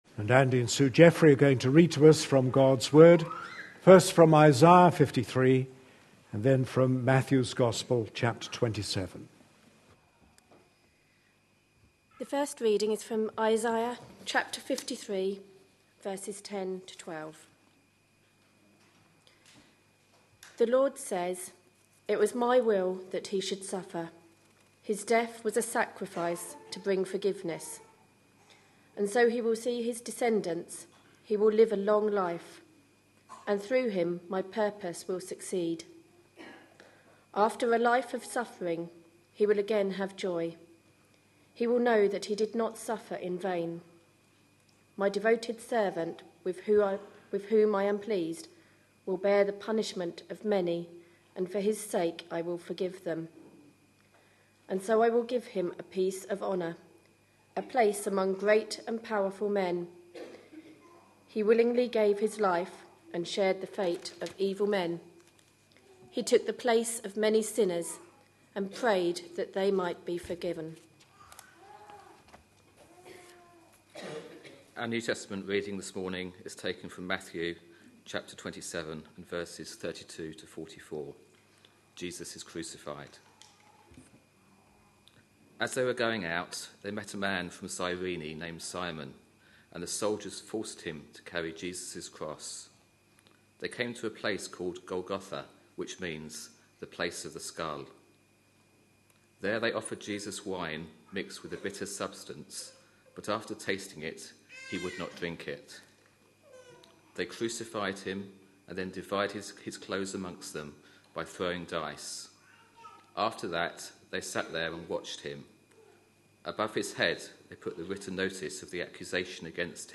A sermon preached on 24th March, 2013, as part of our Passion Profiles and Places -- Lent 2013. series.